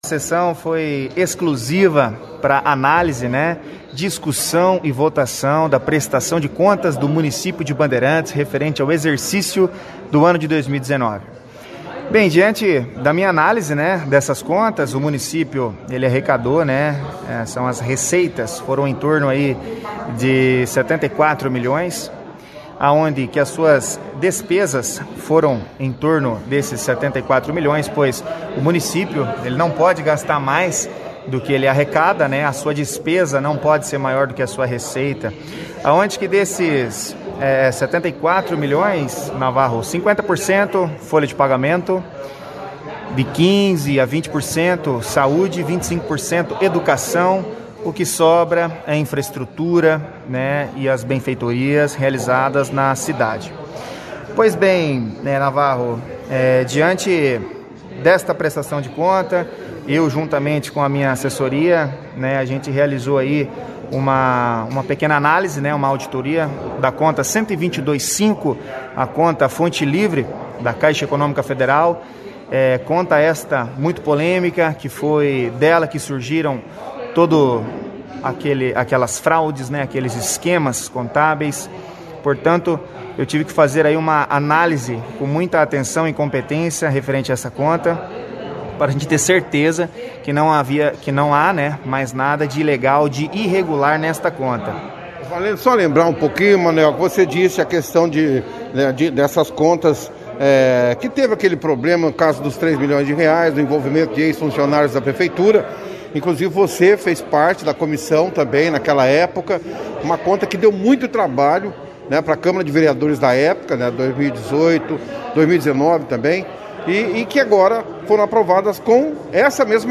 O presidente da casa vereador Mano Viera, (foto), único voto com ressalva, participou da 1ª edição do jornal Operação Cidade desta quarta-feira, 07/03, falando sobre a sessão.